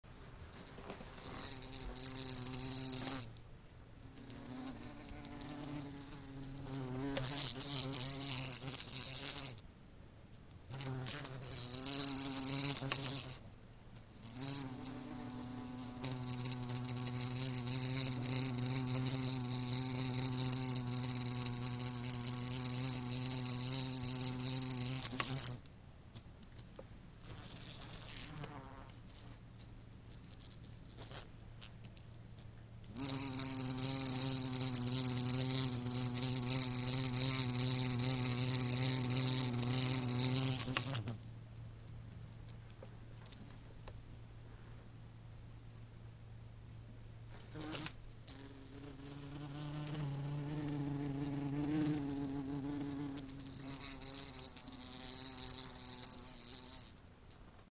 Шмель летает возле окна
• Категория: Шмель
• Качество: Высокое